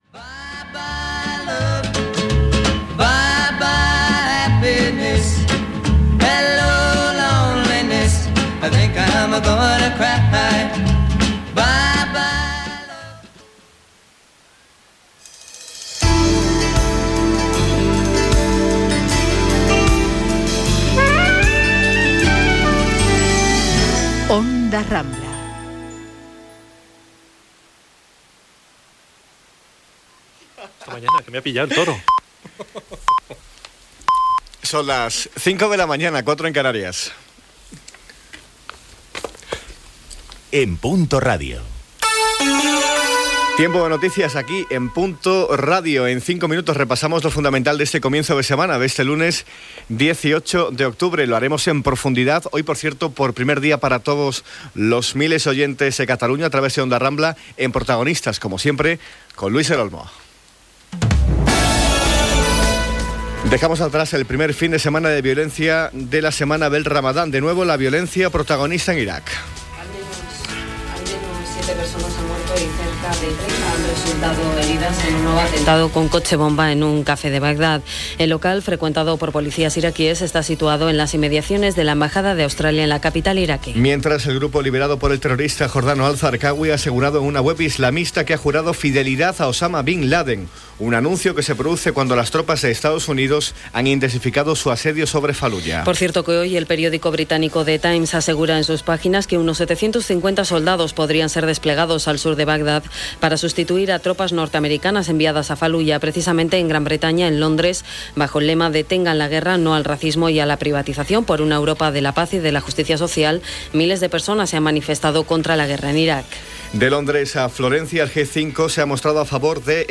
Hora, indicatiu de Punto Radio, data, benvinguda a l'audiència de Catalunya, notícies: la Guerra d'Iraq, expulsió de persones considerades terroristes de la Unió Europea, dades de l'atemptat de l'11 de març a Madrid, Unió Democràtica de Catalunya, etc. El temps, resultat dels sortejos de les rifes, indicatiu.
Gènere radiofònic Informatiu